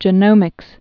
(jə-nōmĭks)